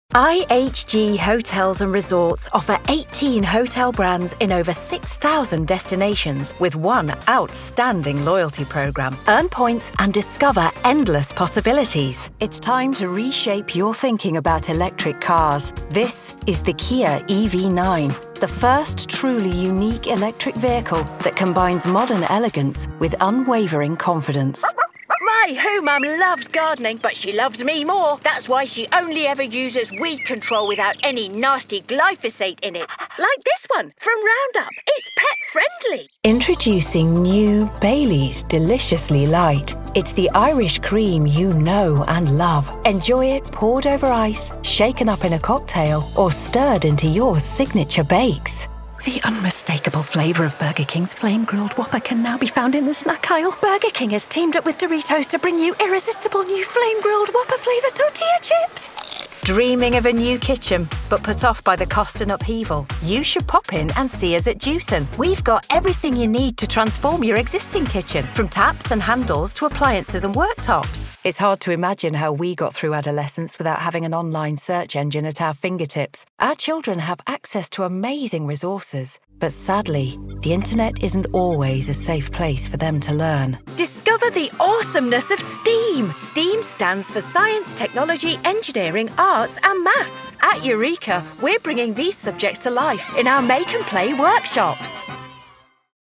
Female
British English (Native)
Assured, Bright, Confident, Corporate, Engaging
dry studio read.mp3
Microphone: SE2200
Audio equipment: Focusrite 4i4 interface, Studiobricks Booth